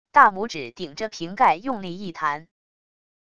大拇指顶着瓶盖用力一弹wav音频